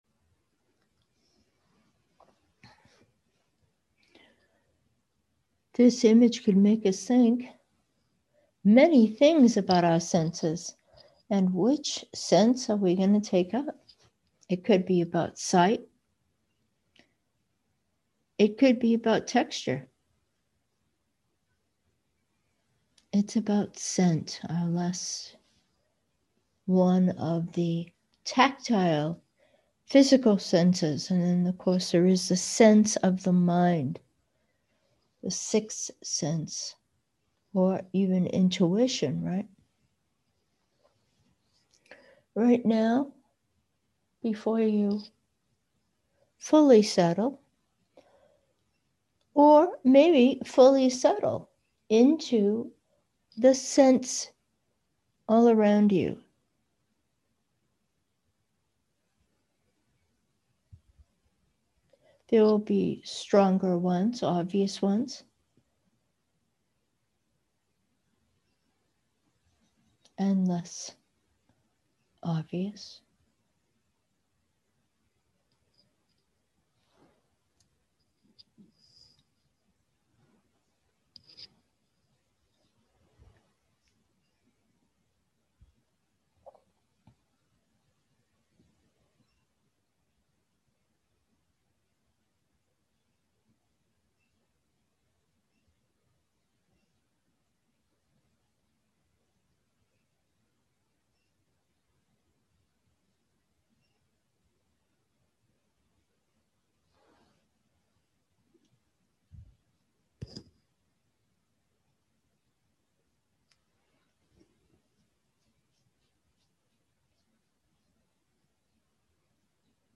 Meditation: scent/smell and awareness